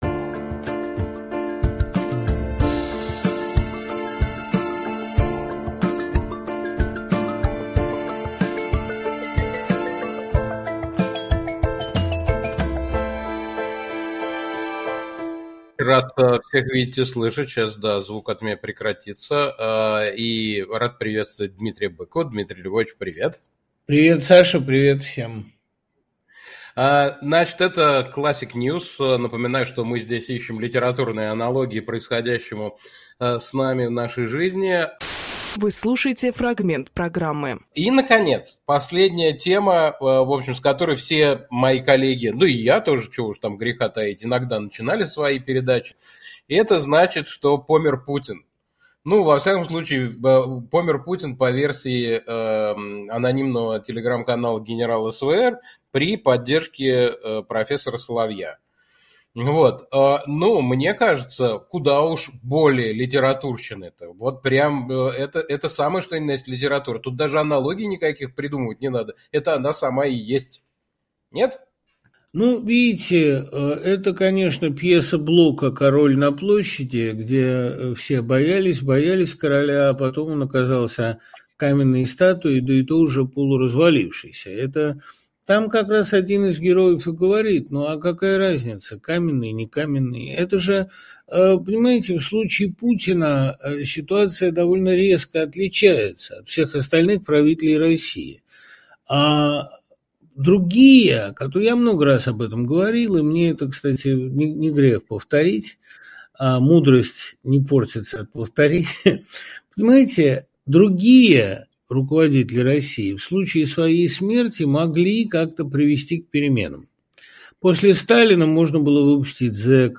Фрагмент эфира от 29 октября.